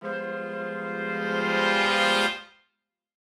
Index of /musicradar/gangster-sting-samples/Chord Hits/Horn Swells
GS_HornSwell-G7b2sus4.wav